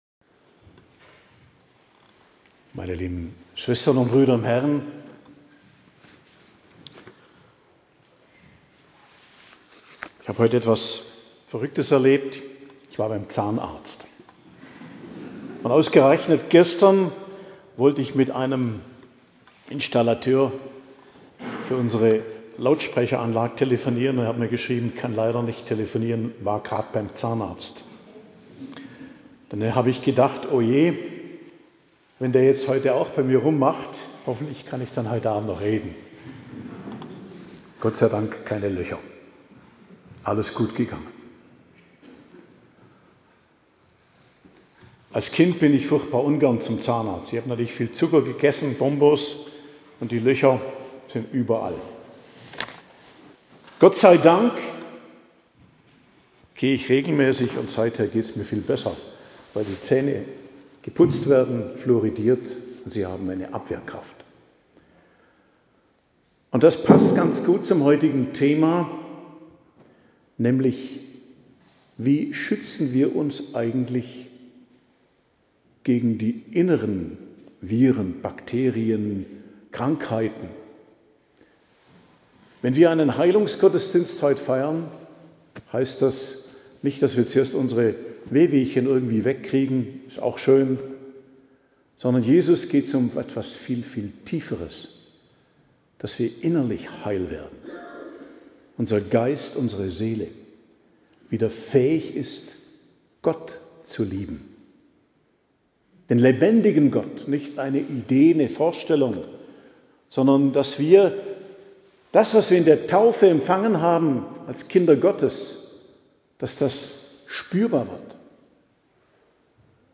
Predigt zum Segnungsgottesdienst in der Wallfahrtskirche "Aufhofener Käppele", Schemmerhofen,17.10.2025 ~ Geistliches Zentrum Kloster Heiligkreuztal Podcast